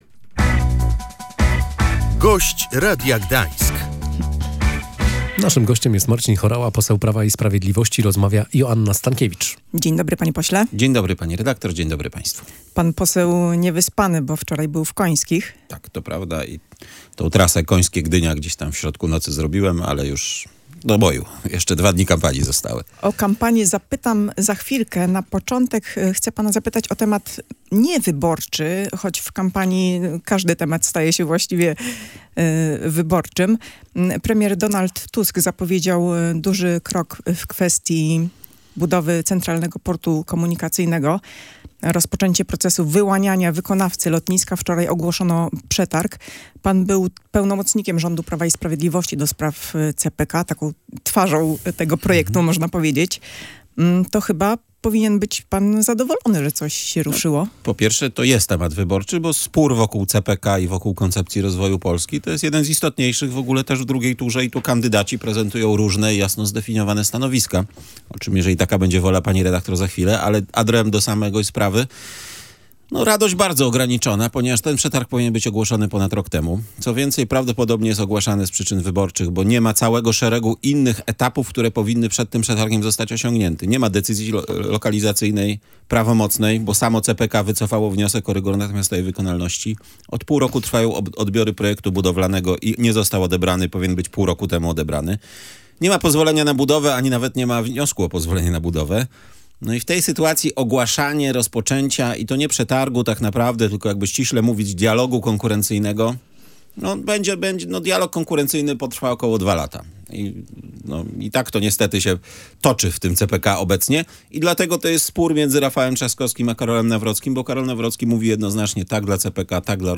Gość Radia Gdańsk i były pełnomocnik rządu ds. CPK podkreśla, że nie ma całego szeregu innych etapów, które powinny przed tym przetargiem zostać osiągnięte.